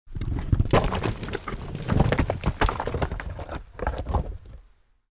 Rockslide-2.mp3